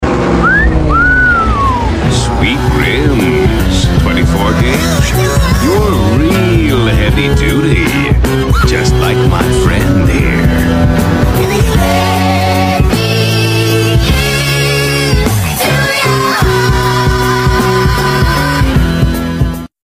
just for the whistle